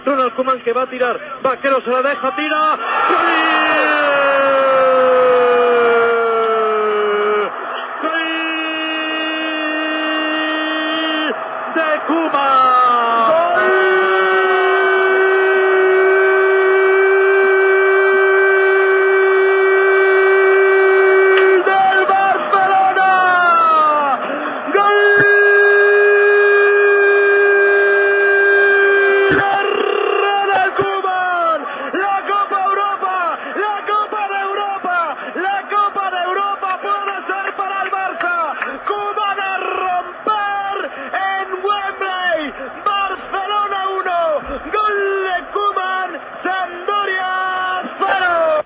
Narració des de l'estadi de Wembley del gol de Ronald Koeman, del Futbol Club Barcelona, a la pròrroga del partit de la final contra la Sampdòria que va donar el títol de la Copa d'Europa al Barça.
Esportiu